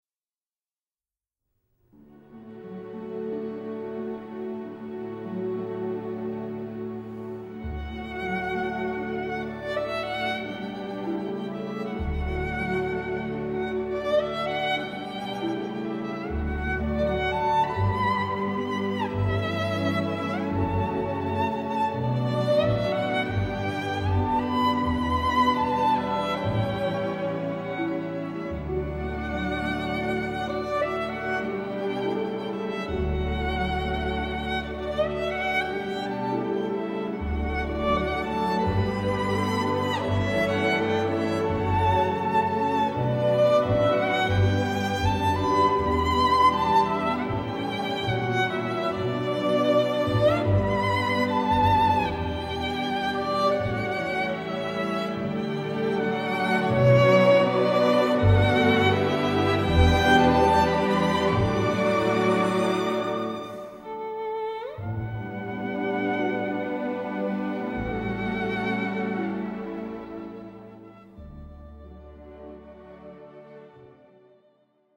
ヴァイオリン+ピアノ